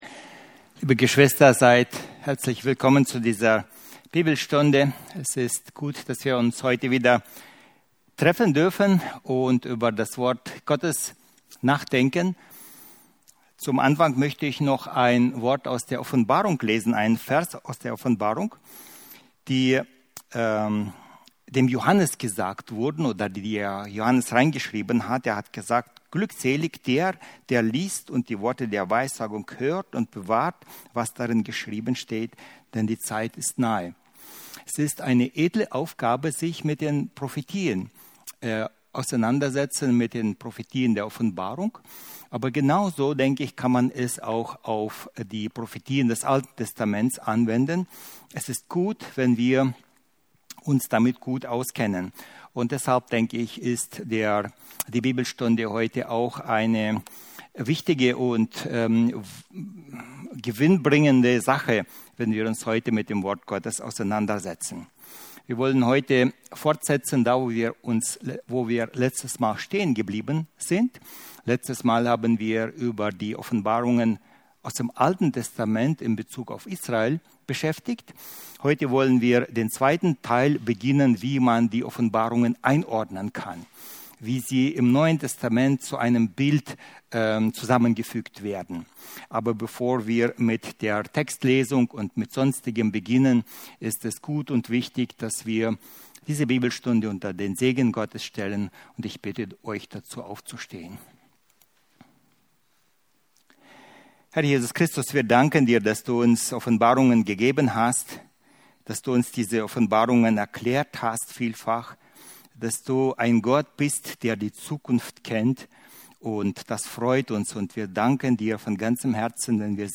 1-13 Dienstart: Predigten Die Vorbereitung auf den Dienst in der Wüste 15.